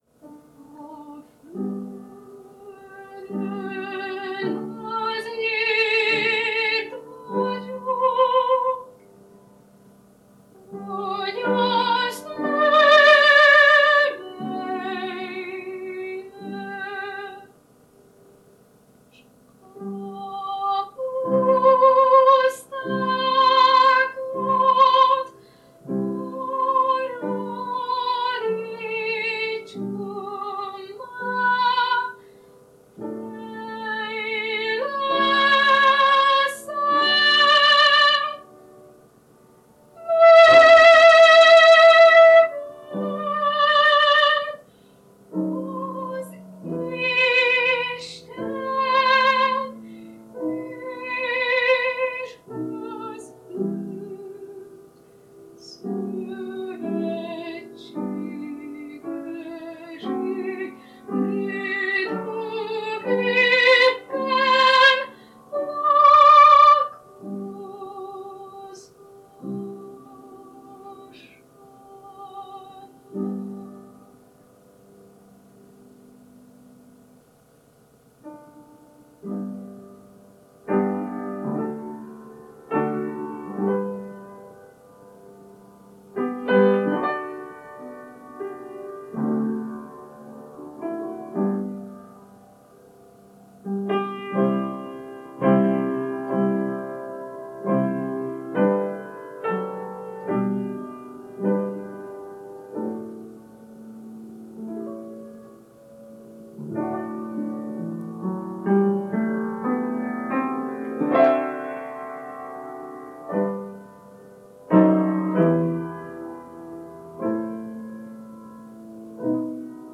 Ének
Zongora